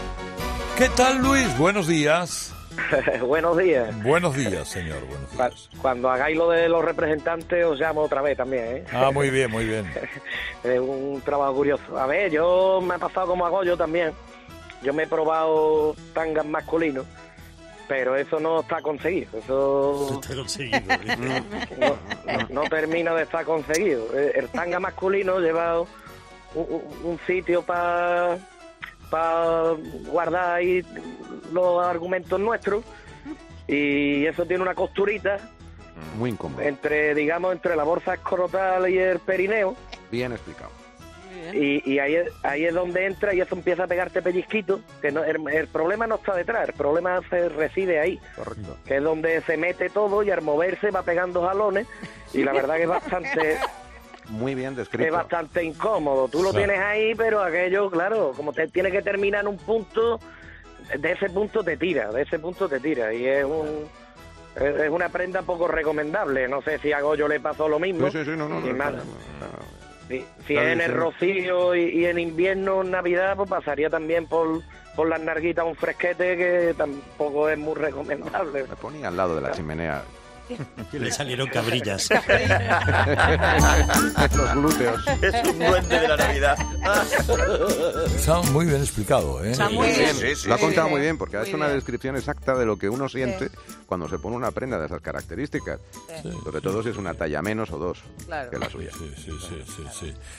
Este martes los oyentes han hablado de sus gustos más extravagantes en lo que a lencería se refiere